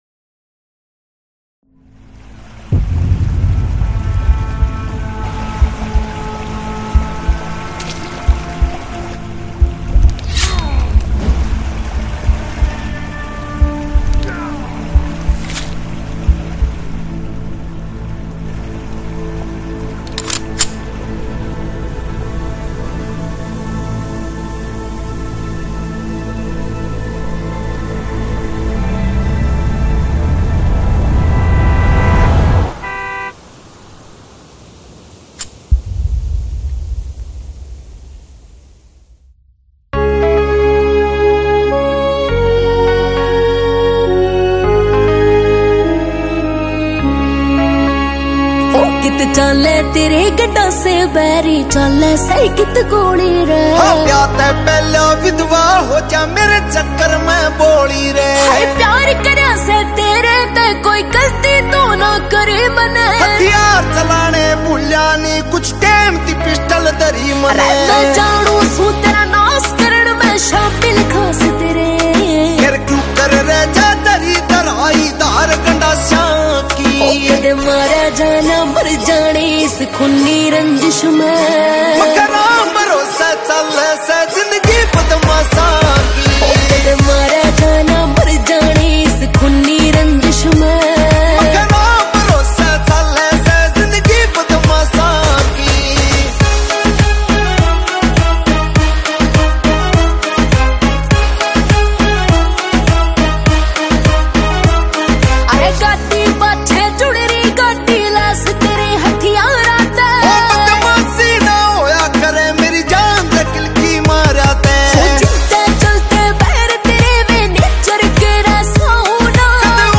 Releted Files Of Haryanvi